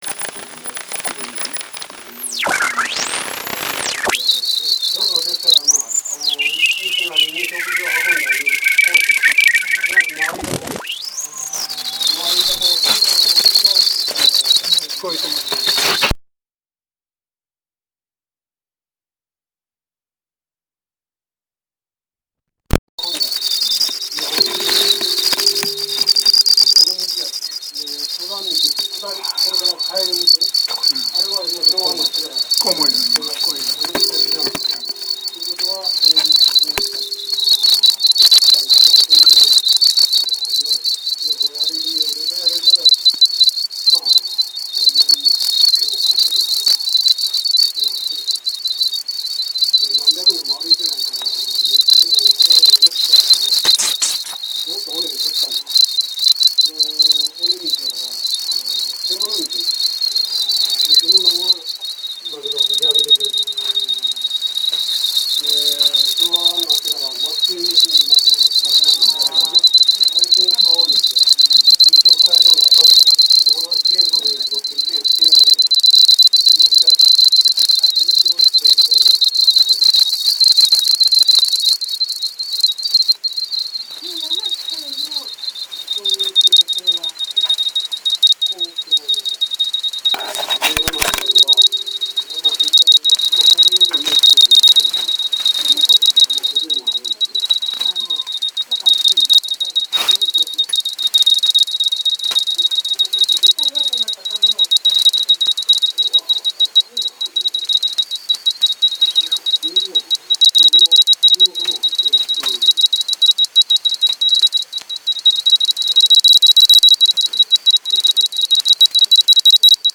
オトチの洞穴のコウモリ
奥の方に明かり窓のような隙間があって、そこからコウモリが出入りしている。 天井にぶら下がって、 コウモリ同士しゃべっている声 を録音することができた。